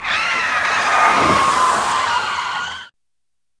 skid2.ogg